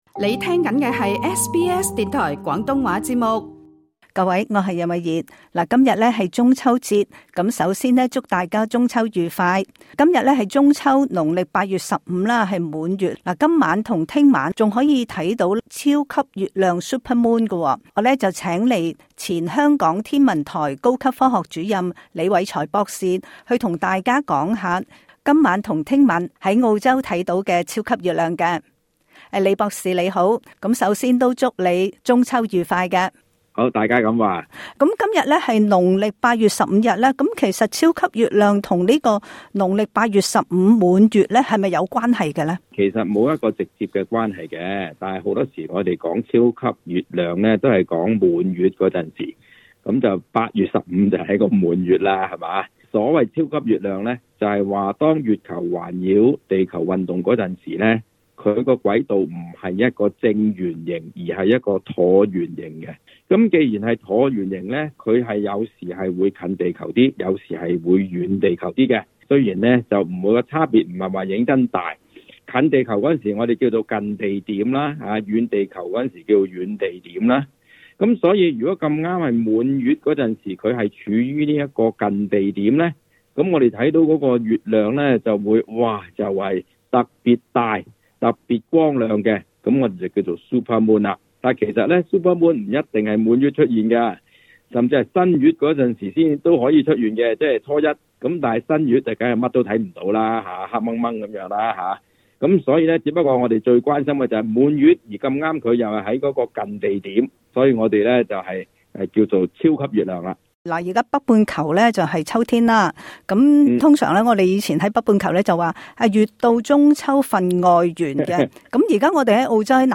詳情請聽今集訪問。